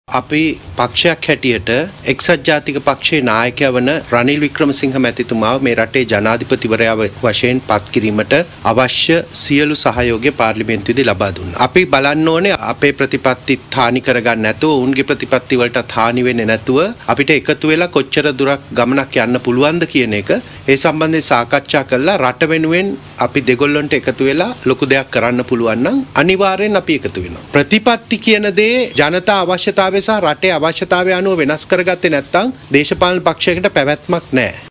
බත්තරමුල්ල ශ්‍රී ලංකා පොදුජන පෙරමුණු පක්ෂ කාර්යාලයේ පැවති මාධ්‍ය හමුවකට එක්වෙමින් පාර්ලිමේන්තු මන්ත්‍රී, නීතඥ සාගර කාරියවසම් මහතා මේ බව කියා සිටියා.